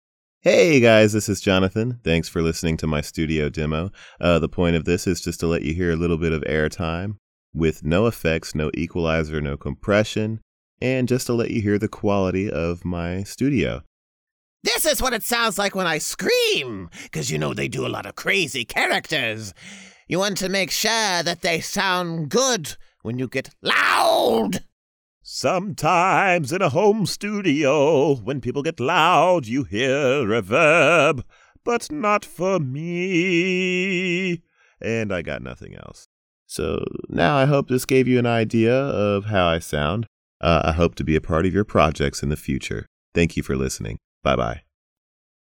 Male
Teenager (13-17), Adult (30-50)
Character / Cartoon
Studio Quality Sample
Character, Cartoon Voice Overs